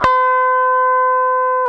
GTR ELGTRC06.wav